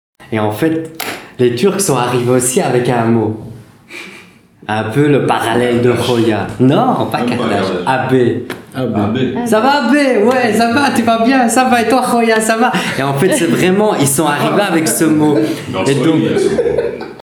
Mon frère, salutation en turc pour les jeunes du quartier (prononcé 'abé’).